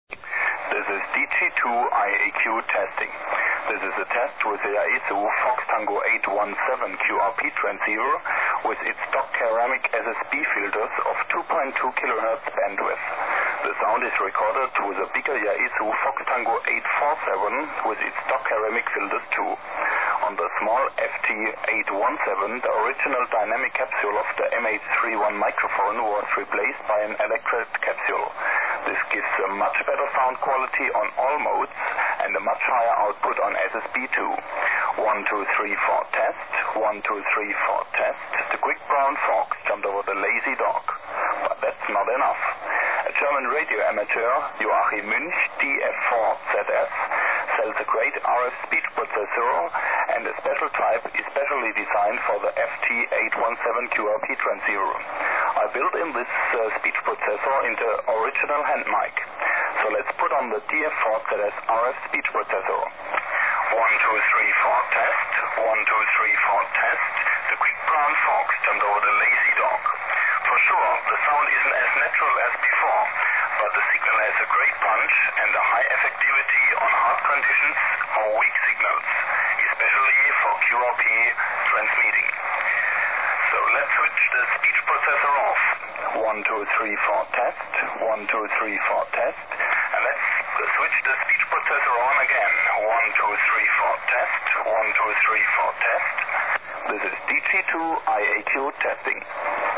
Jak takov� HF kompresor funguje s FT817?
FT817sound.mp3